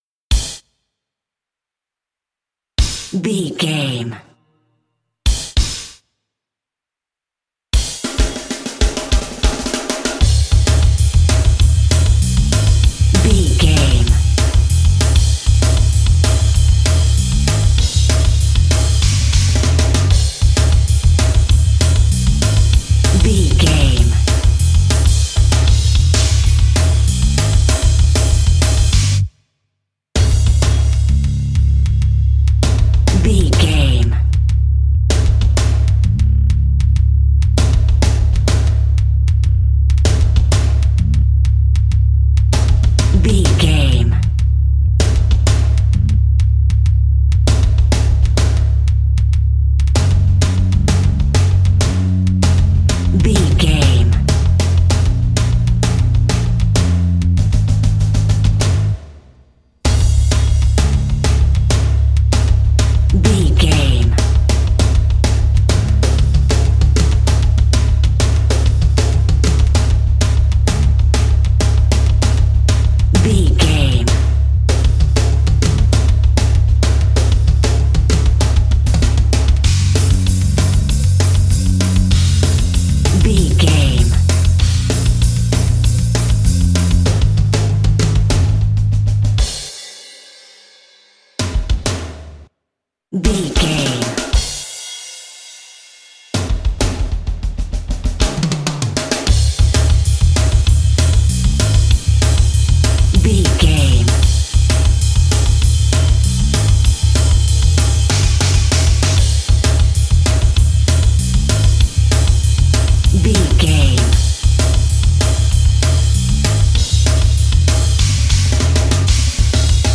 Fast paced
Aeolian/Minor
C♯
Fast
distortion